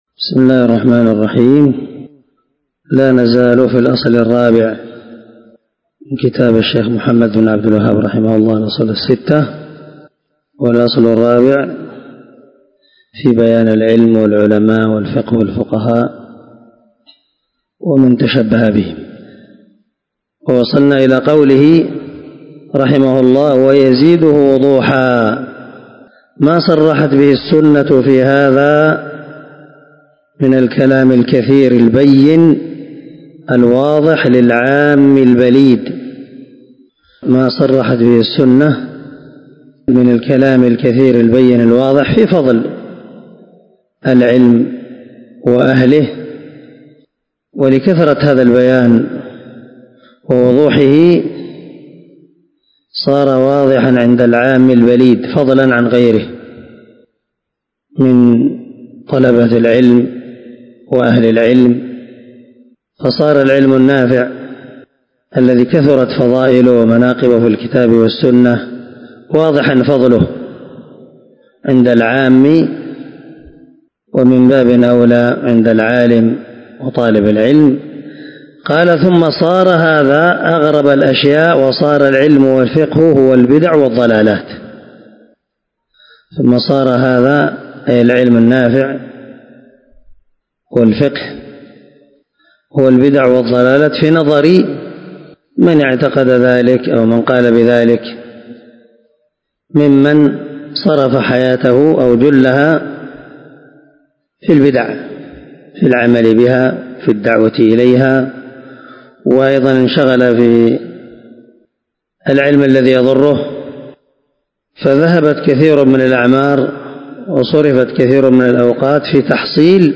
🔊 الدرس 8 من شرح الأصول الستة ( تابع الأصل الرابع)